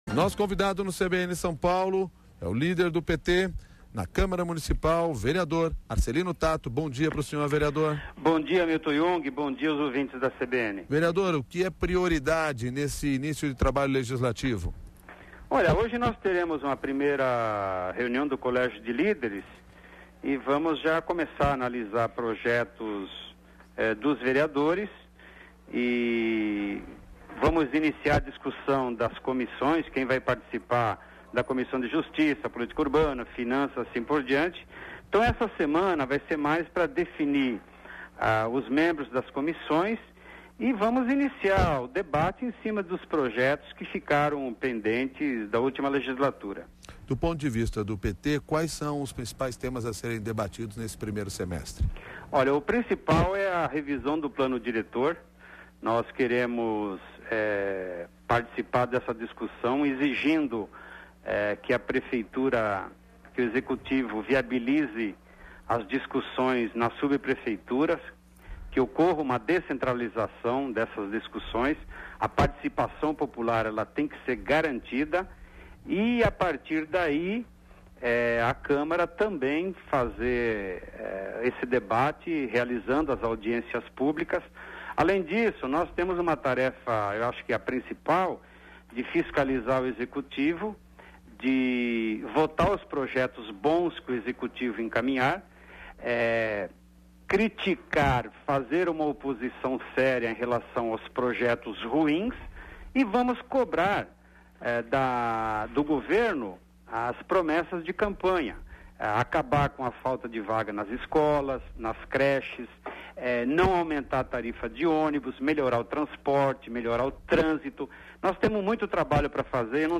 Ouça a entrevista com o vereador